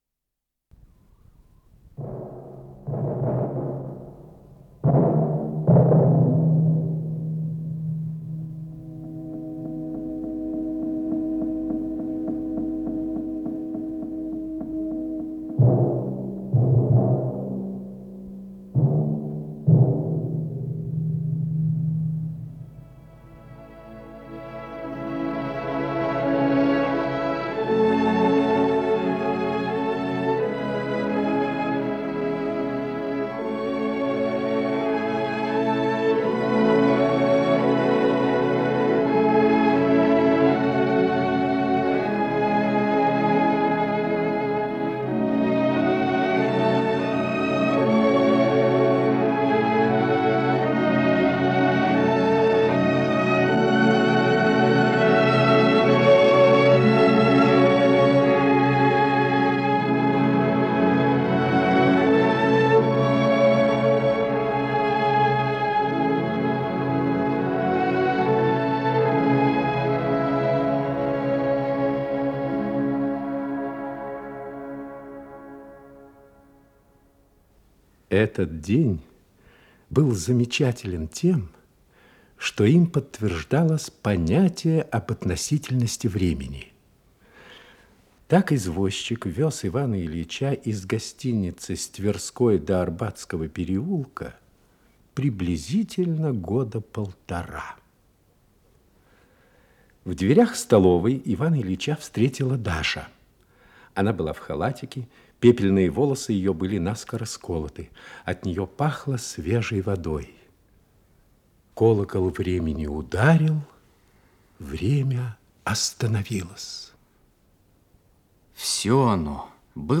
Исполнитель: Артисты московских театров
"Хождение по мукам", инсценированные страницы трилогии